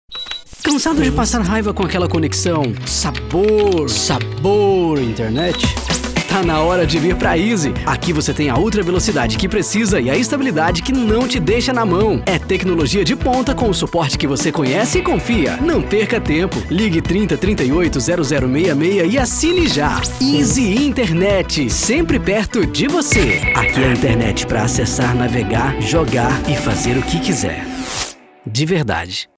Institucional - VT e Radio: